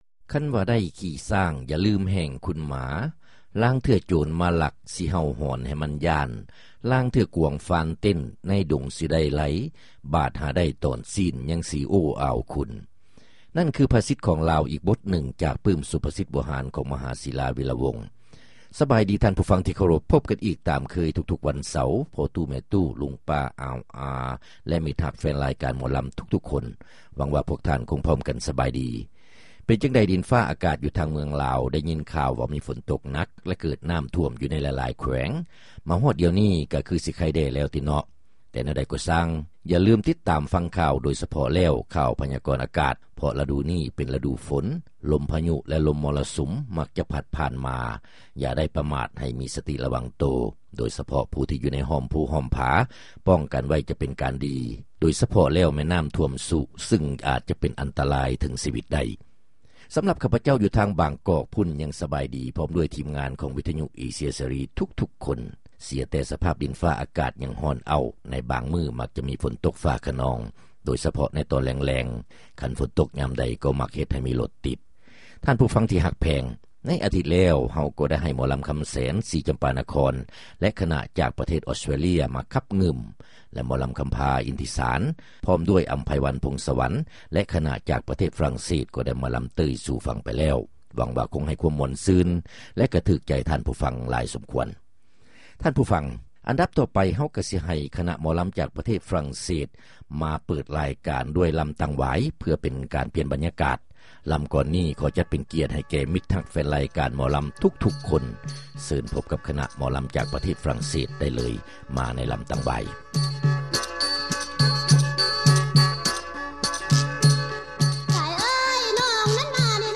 ຣາຍການໜໍລຳ ປະຈຳສັປະດາ ວັນທີ 12 ເດືອນ ສິງຫາ ປີ 2005